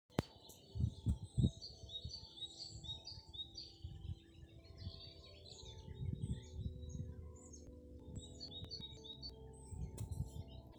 Great Tit, Parus major
Ziņotāja saglabāts vietas nosaukumsLīgatnes pag., Ķempji
StatusSinging male in breeding season